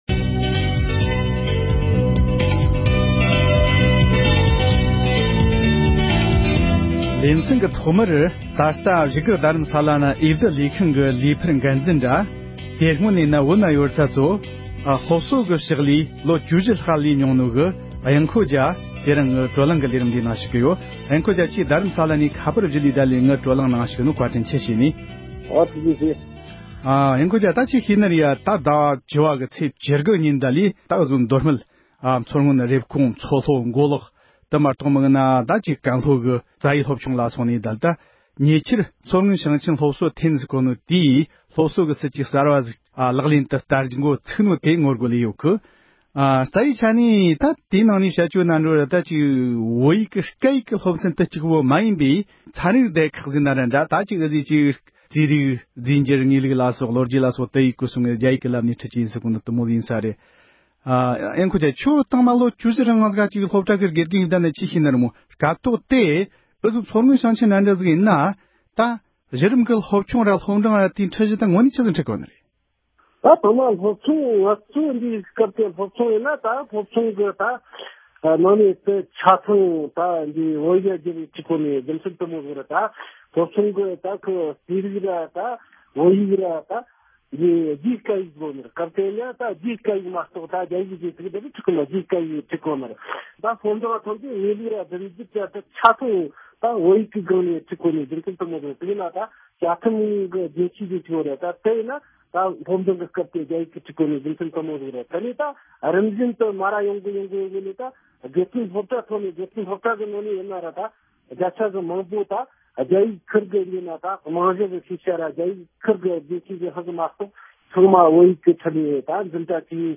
མདོ་སྨད་མཚོ་སྔོན་ས་ཁུལ་ཁག་ཏུ་སློབ་གསོ་བཅོས་བསྒྱུར་ཀྱི་སྲིད་ཇུས་ཐད་གླེང་མོལ།